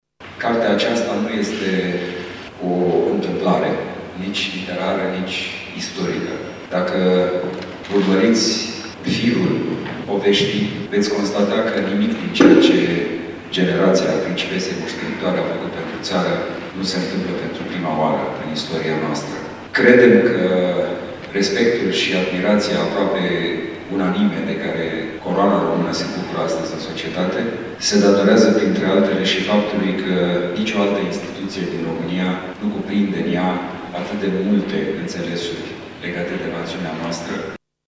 Alteţa Sa Principele Radu a vorbit despre importanţa Casei regale pentru istoria României: